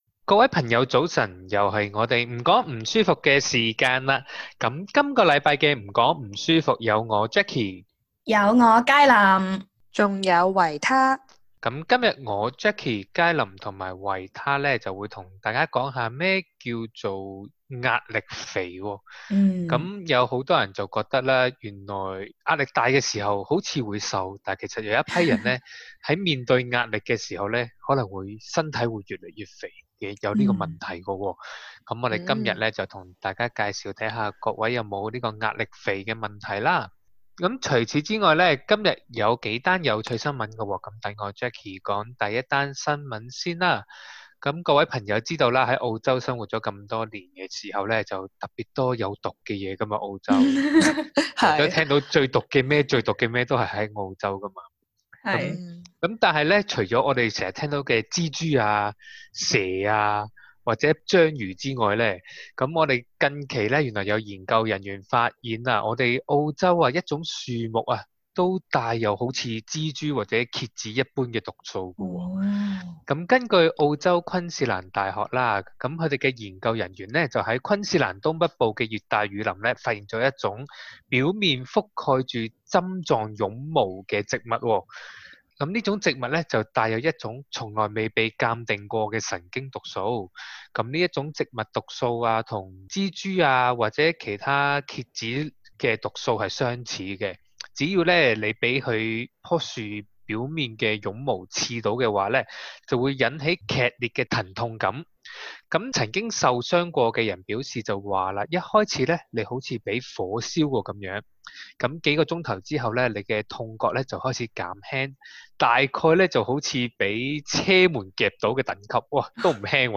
在本集【唔講唔舒服】裡，我們 3 為節目主持熱